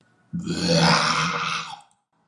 描述：在islaaudio.com编辑的野兽爬行生物怪物
标签： 爬行 怪物 怪物 野兽
声道立体声